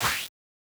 08_Bite_04.wav